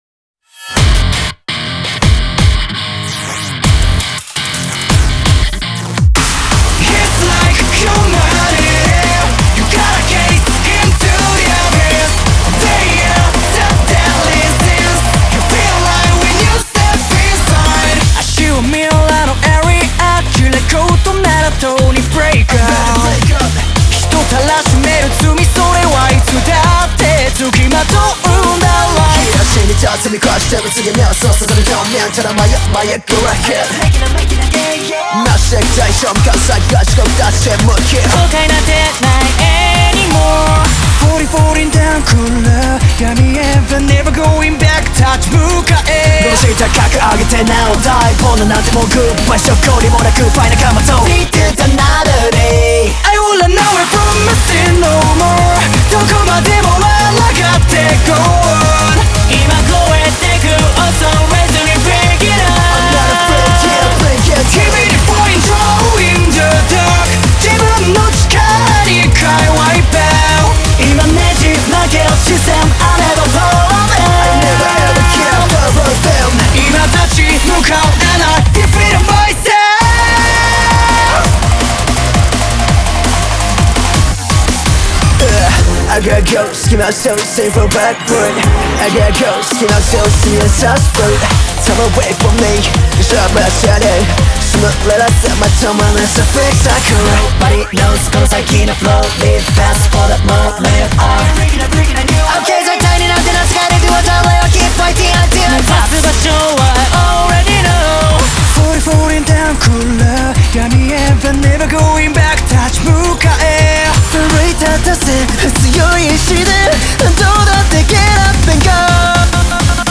Genre : Anime.